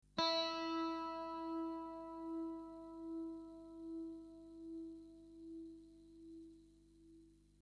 Einfach auf den Link für die jeweilige Gitarrensaite klicken und nach dem Ton stimmen
Hohe E Saite
E hoch.mp3